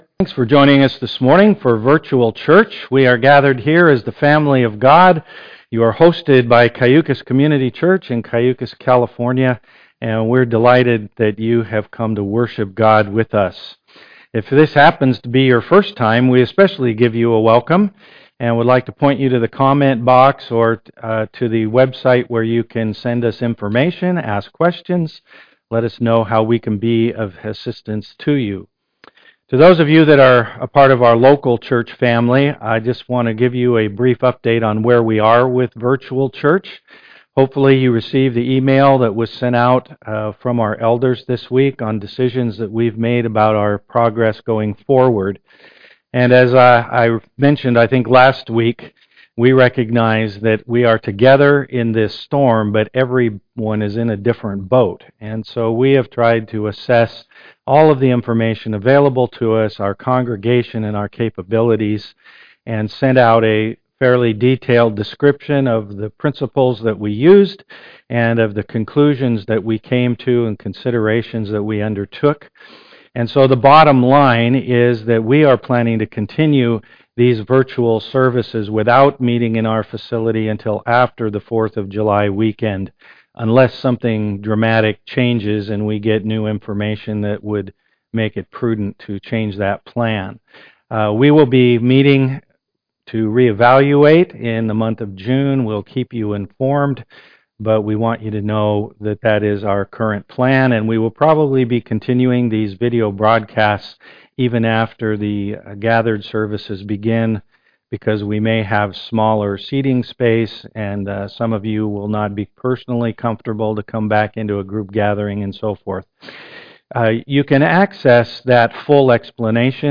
Passage: Psalm 103 Service Type: am worship Click on the links below to enjoy a time of worship prior to listening to the message.